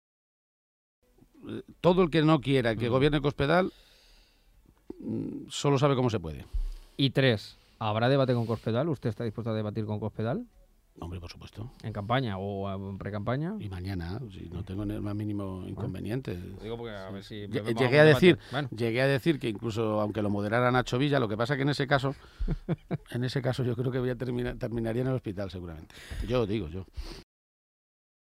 Cortes de audio de la rueda de prensa
Audio Page-entrevista OCR 3